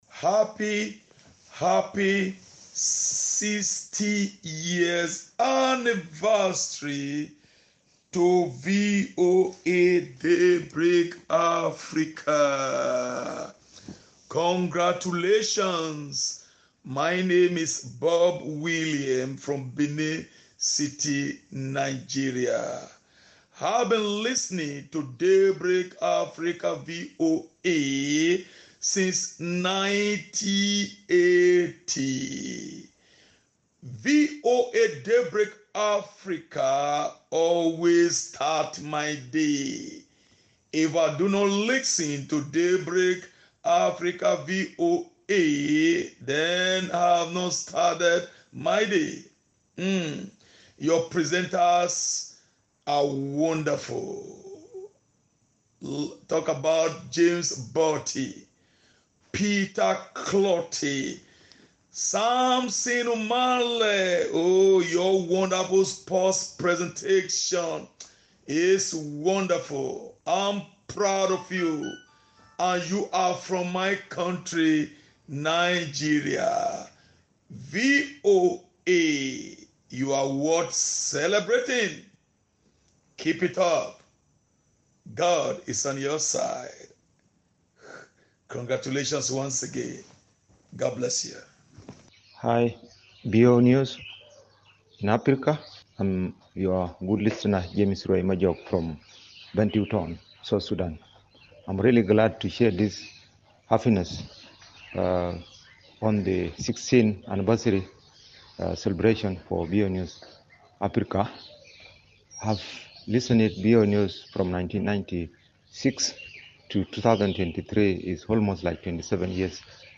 As English to Africa commemorates its 60th year, listeners of our radio programs from across the continent shared their well wishes and messages.